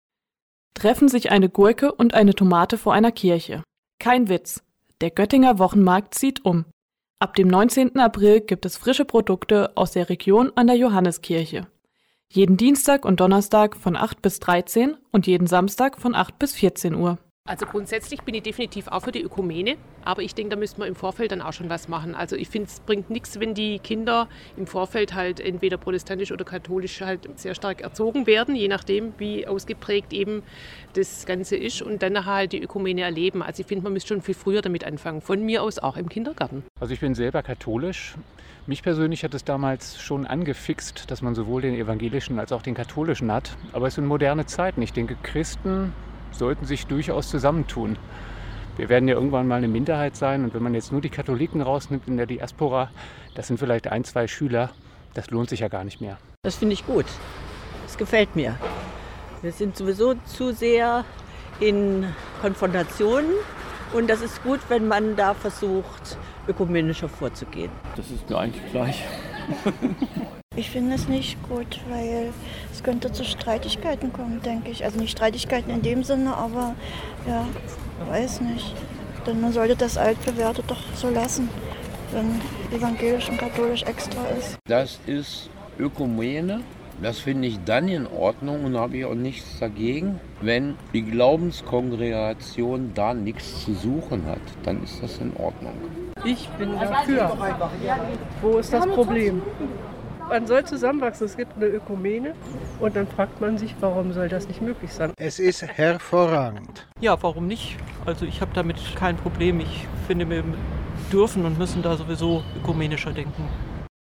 hat sich auf der Straße umgehört, was die Göttinger von der Idee eines gemeinsamen christlichen Religionsunterricht halten.